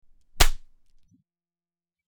Smack
Smack.mp3